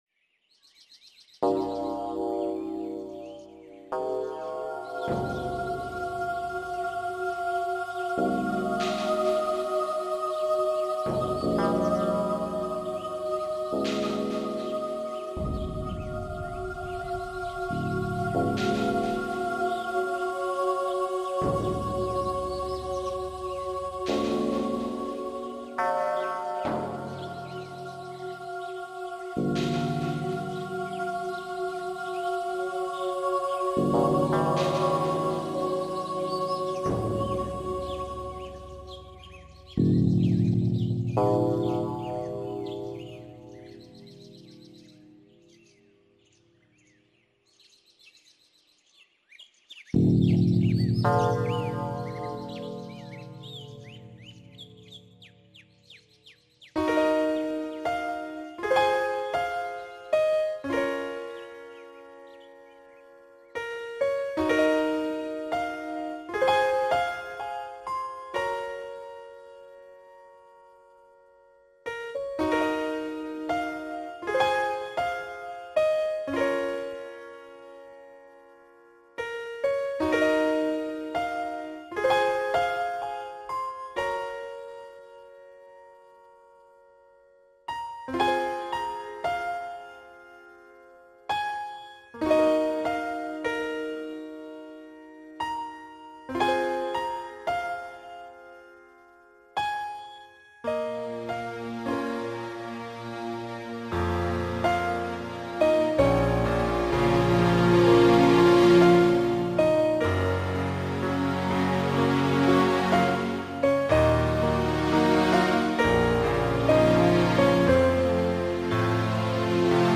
纯音乐
个人感觉既恢弘又凄婉